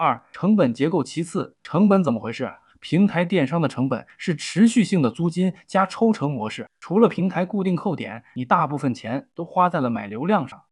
Narracja Reklamowa
Przekonujący Ton